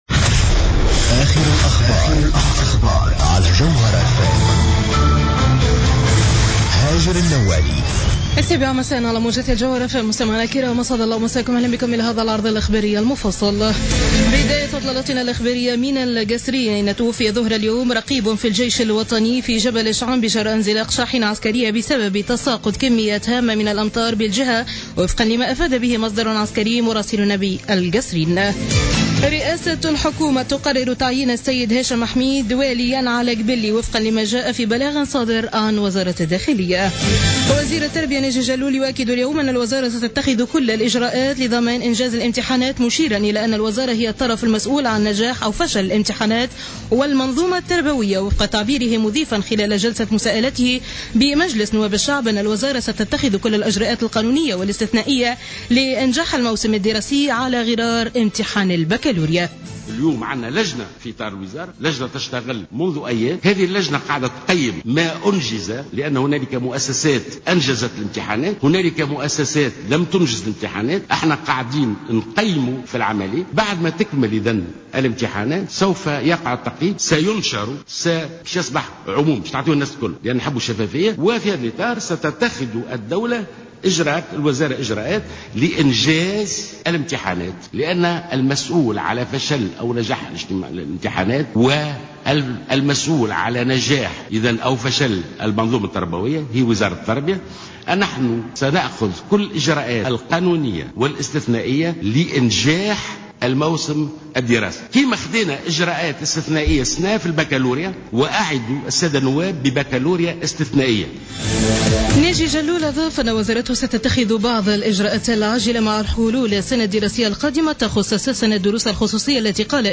نشرة أخبار السابعة مساء ليوم الإربعاء 10 جوان 2015